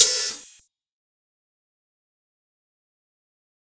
quik open hat .wav